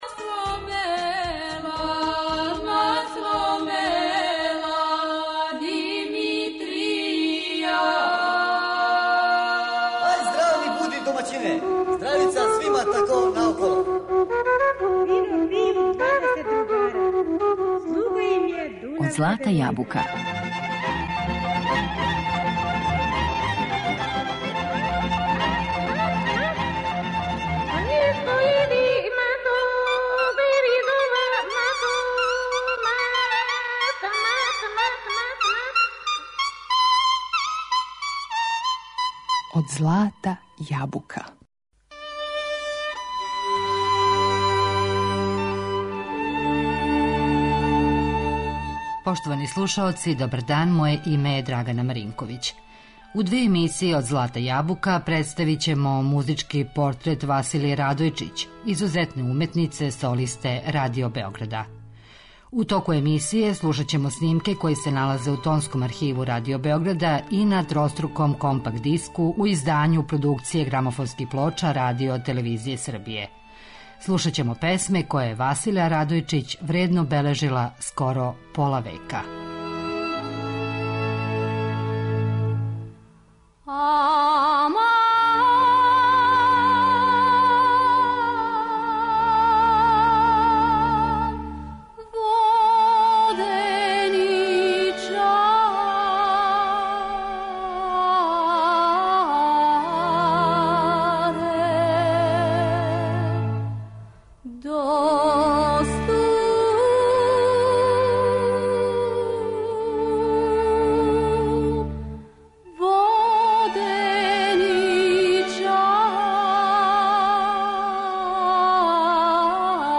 Музички портрет Василије Радојчић
Певачку каријеру почела је на Радио Београду 1958.године. Репертоар јој је био веома богат, али мелодије из јужне Србије, обојене њеним аутентичним извођењем, сврстале су је у најпознатијег извођача врањанског мелоса.